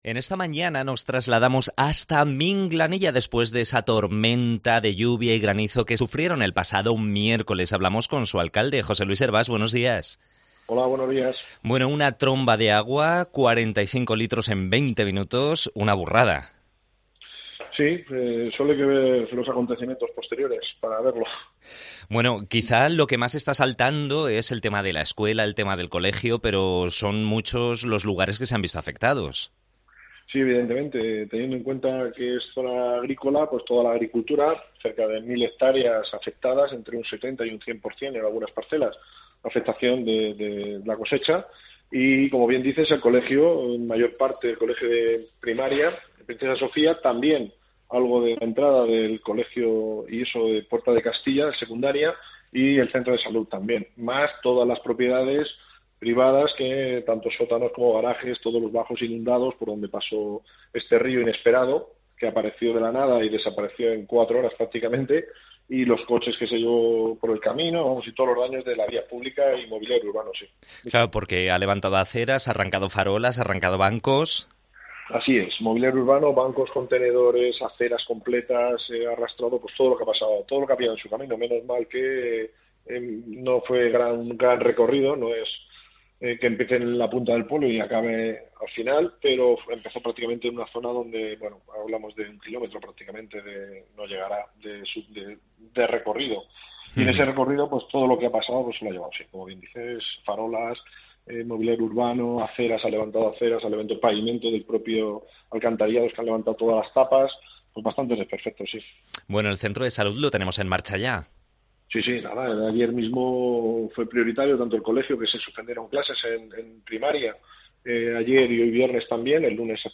Entrevista al alcalde de la localidad de Minglanilla ante las tormentas que sufrió el municipio el pasado miercoles